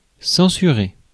Ääntäminen
IPA: [sɑ̃.sy.ʁe]